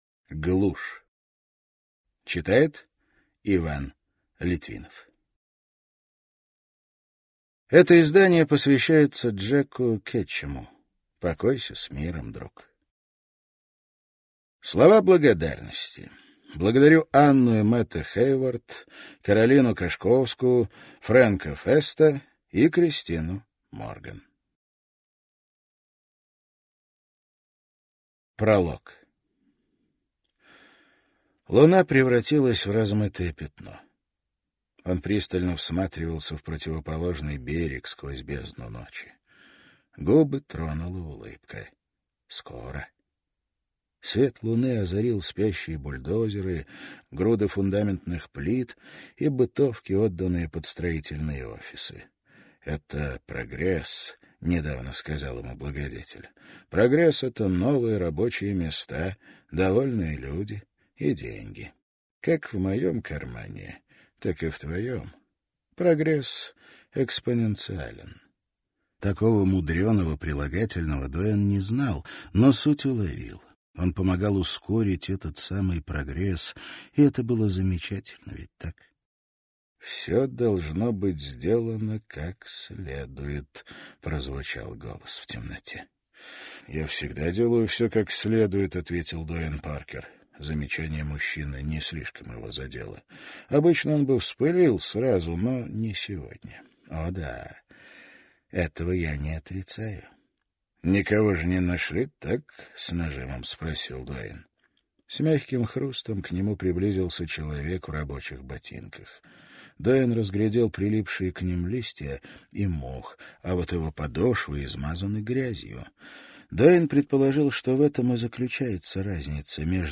Аудиокнига Глушь | Библиотека аудиокниг